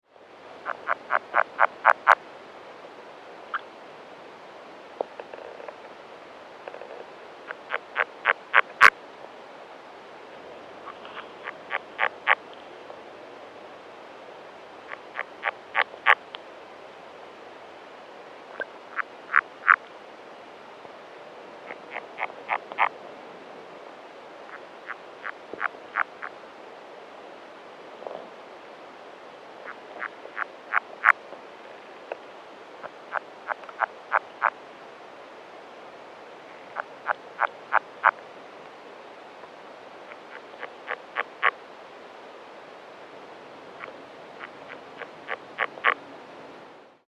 These calls were barely audible in the air, and were very faint underwater, as far as I could determine. 6 or 7 frogs were calling from the area, but the calls were infrequent with long stretches of silence between them.
Sound  This is a 47 second underwater recording of the advertisement calls of one or more frogs. The time has been shortened: the original recording was much longer and much of the silence between calls has been removed.